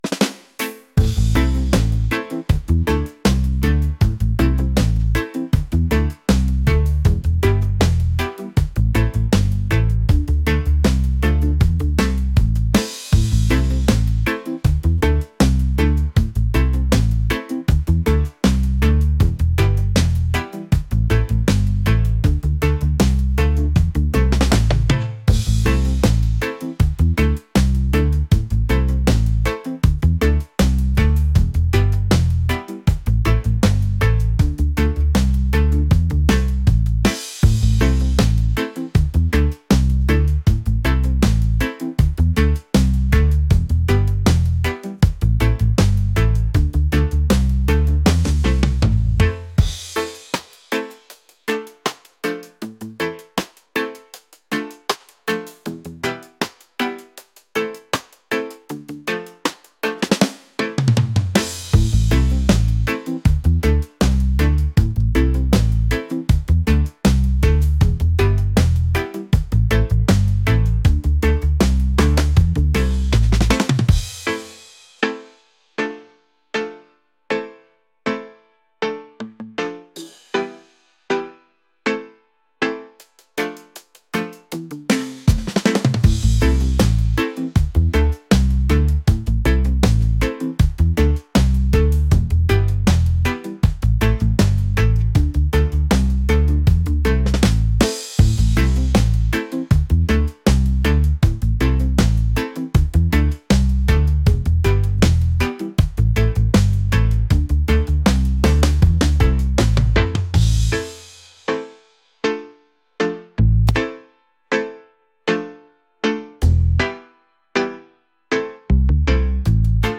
reggae | groovy | laid-back